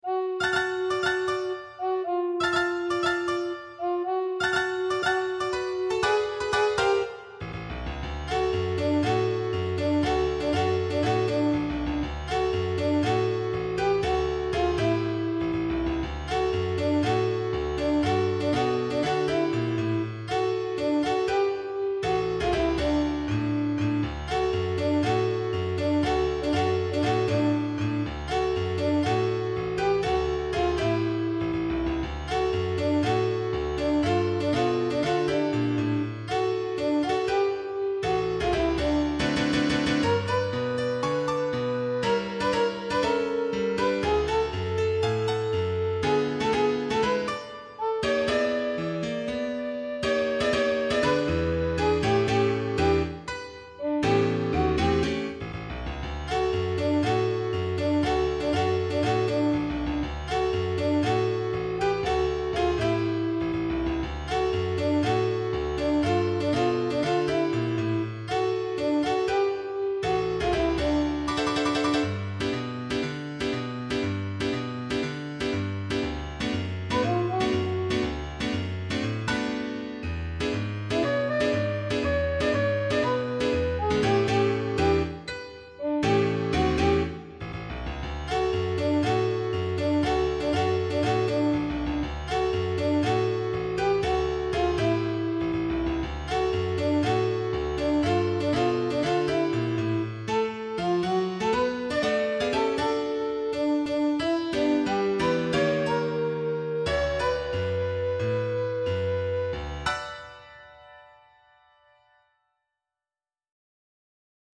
SecondSoprano